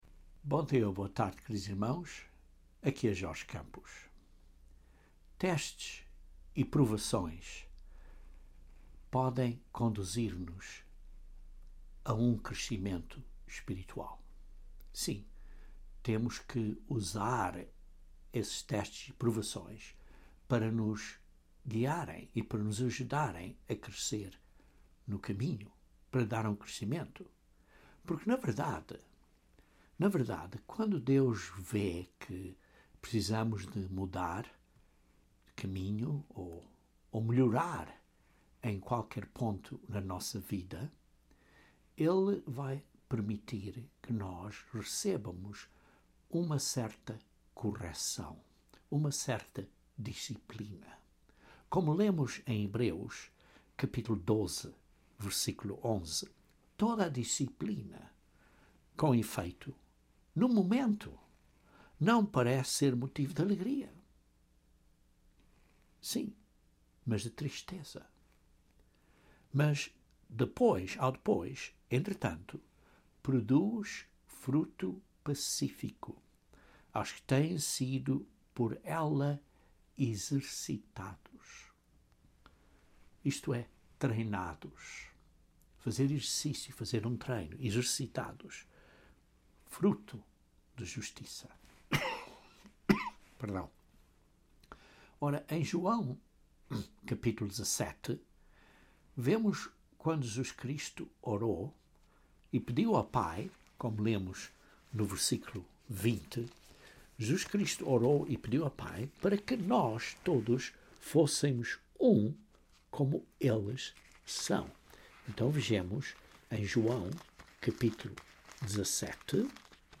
Este sermão aborda duas chaves criticamente importantes no processo do nosso crescimento espiritual.